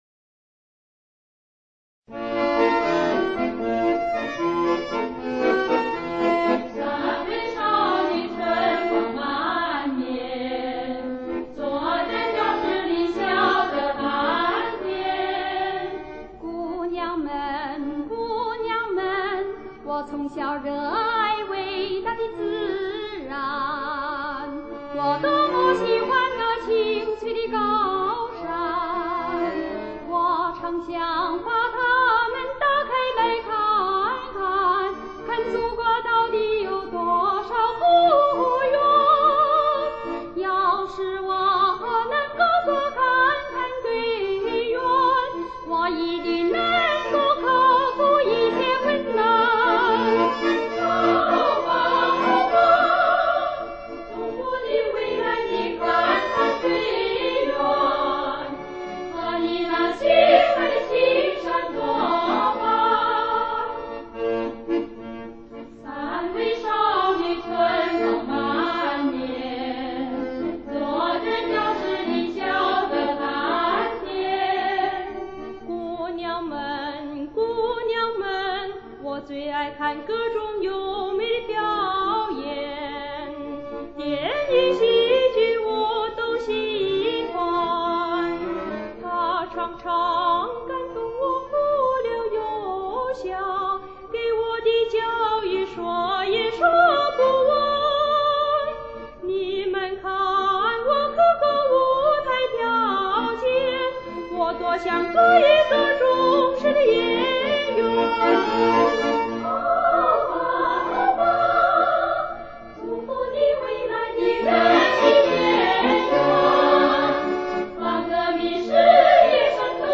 女声小合唱